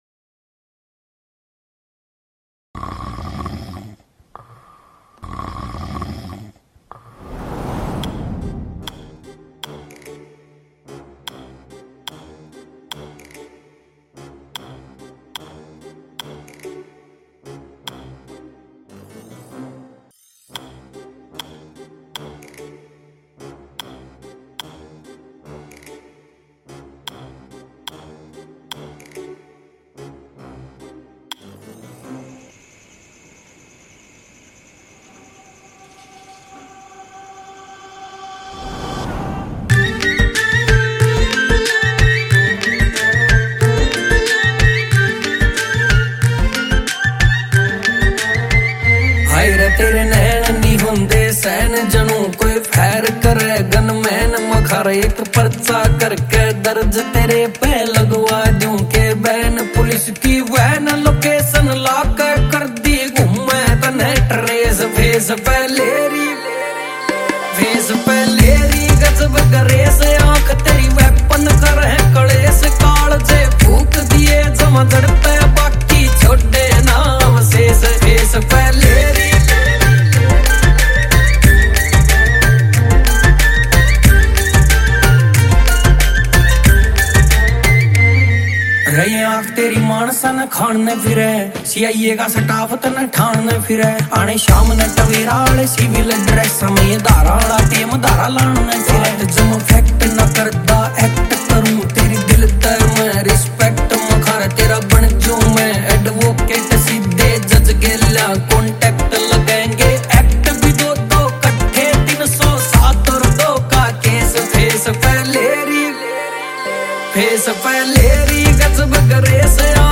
Rap
Haryanvi